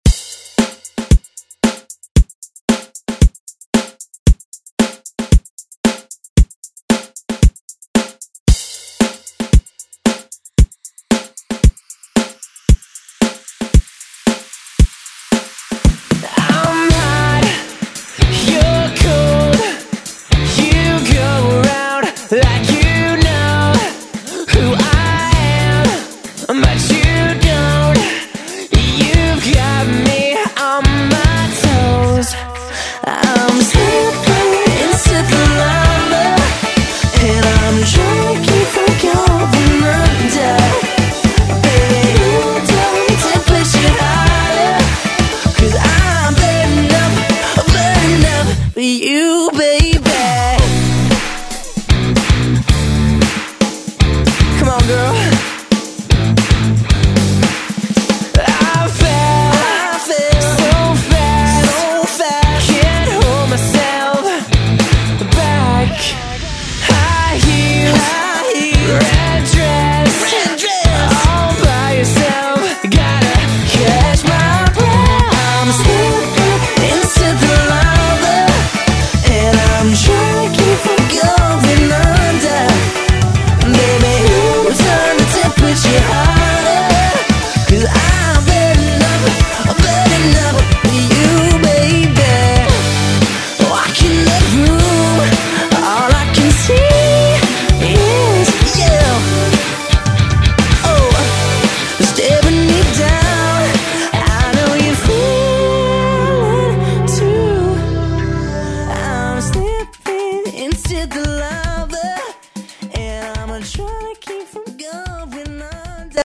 Electrpnic Pop Music Extended ReDrum Dirty 80 bpm
Genre: RE-DRUM
Dirty BPM: 80 Time